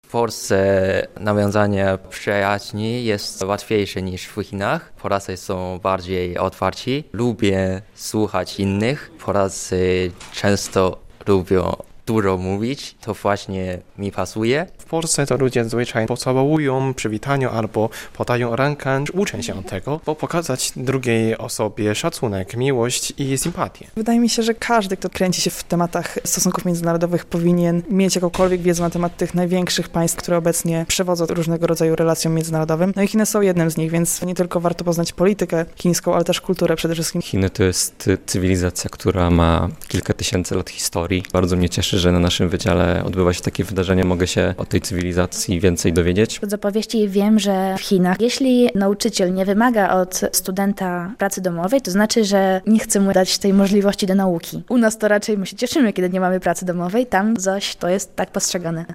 Na Kampusie Uniwersytetu w Białymstoku odbył się Dzień Chiński - wydarzenie stworzone z myślą o fanach kultury Dalekiego Wschodu.
Spotkanie poprowadzili Chińczycy, którzy doskonale znają polski, ponieważ studiowali w naszym kraju na Uniwersytecie Jagiellońskim.